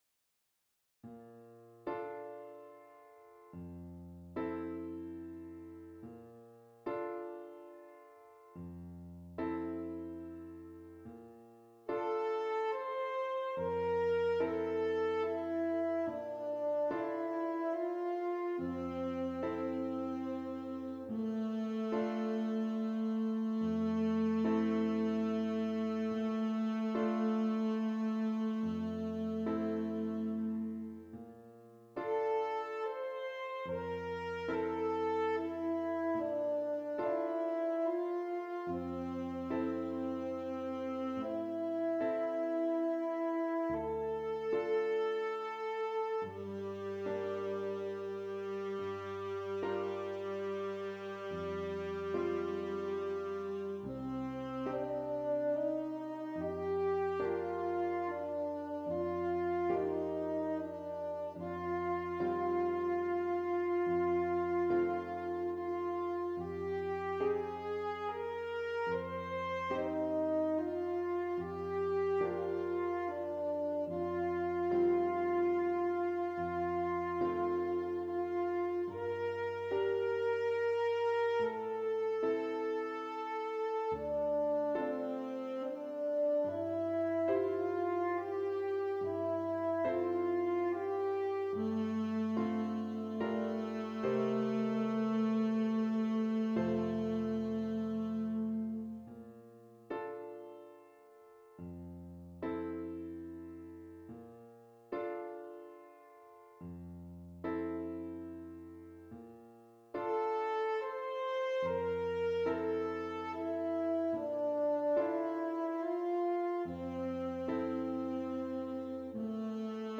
• Alto Saxophone and Keyboard